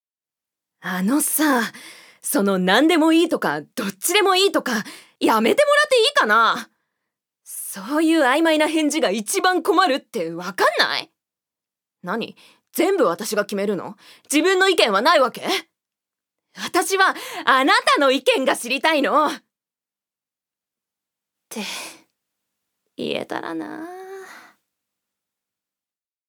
女性タレント
セリフ２